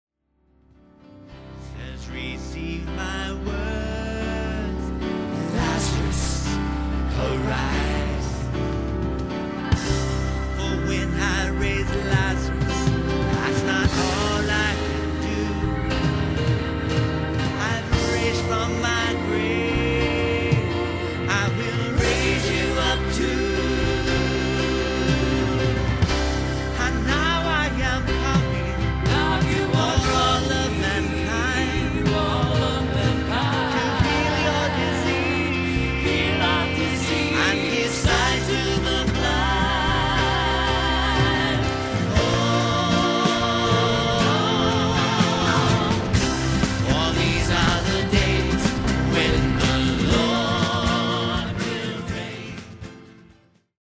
Piano/Keyboard/Hammon B3 Organ & Lead Vocals